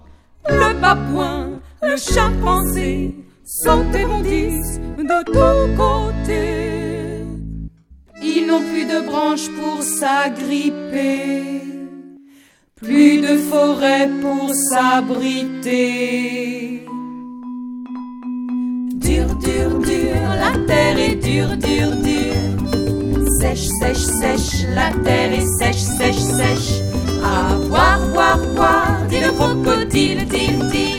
• Chanteur :